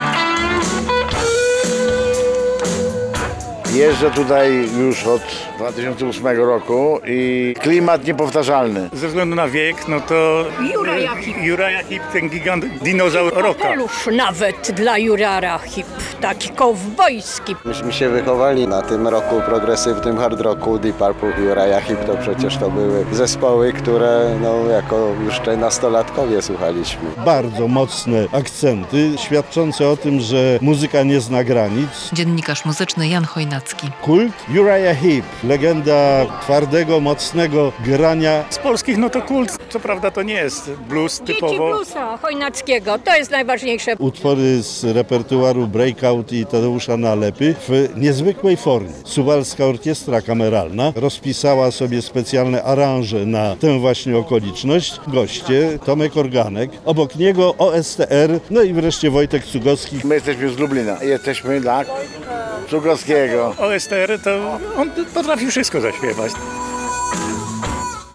Miłość do bluesa i Suwalszczyzny przyciąga tłumy - trwa Suwałki Blues Festival - relacja